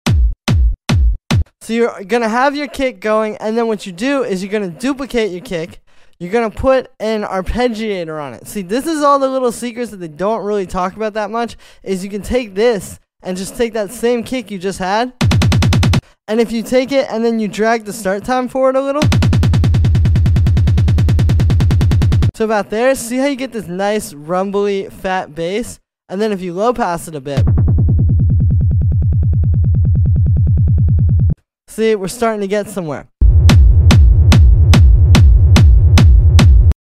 More on techno kicks here sound effects free download